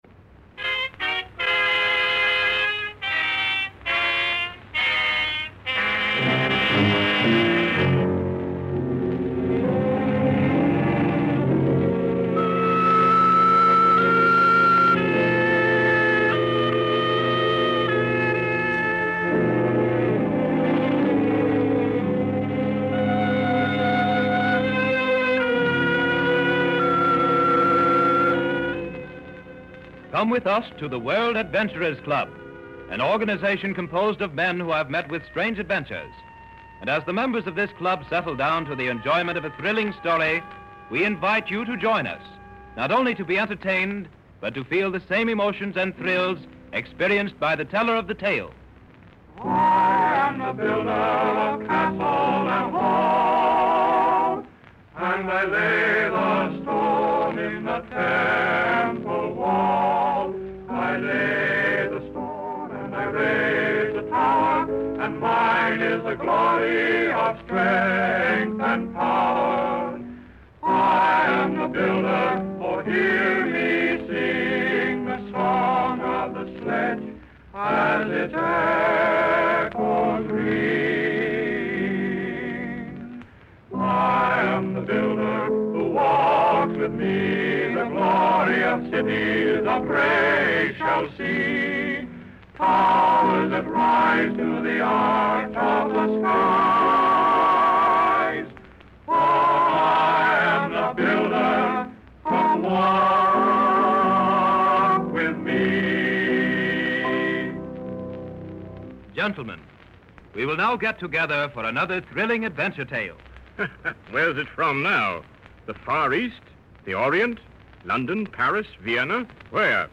Episode 11, titled "The Tattooed Rose," is a gem among its collection, offering a story that is as enigmatic as it is enthralling. The Adventurer's Club series harks back to the golden age of radio, where each episode was a window into exotic locales and heart-pounding escapades.